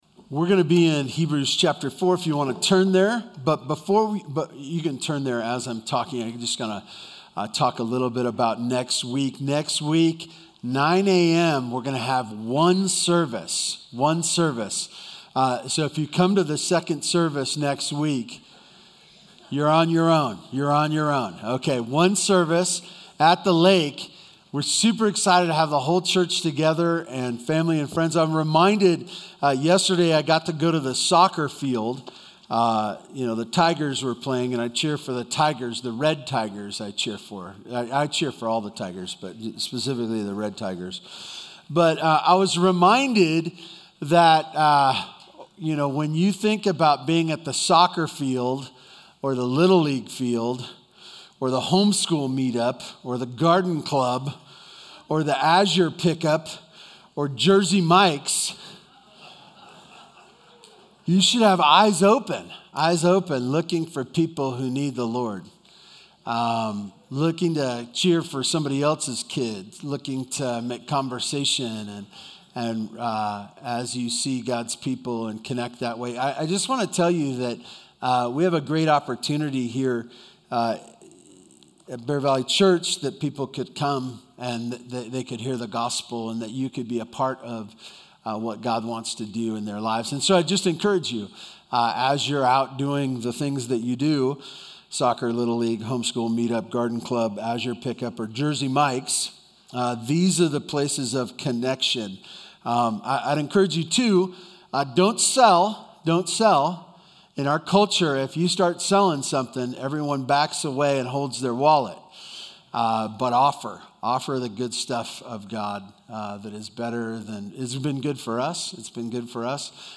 BVC Sunday Sermons